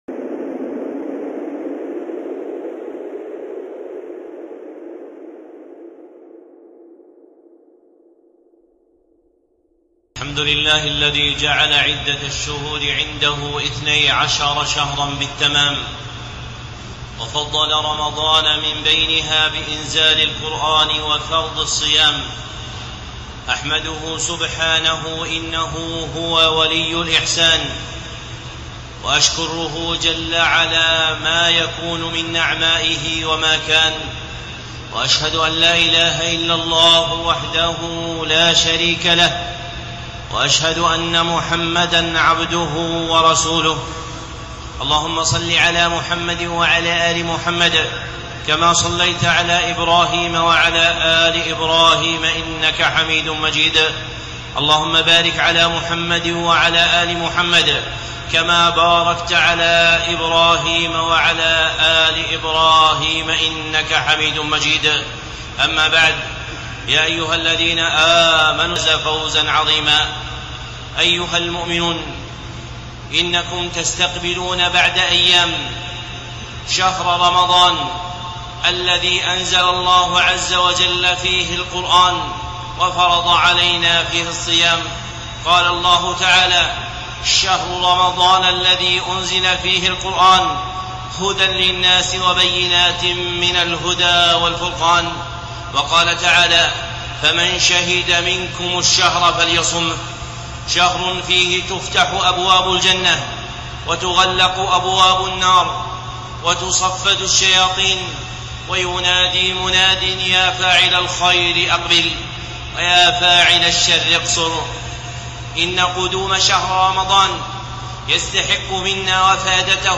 خطبة (استقبال رمضان بين التوفيق والحرمان)
الخطب المنبرية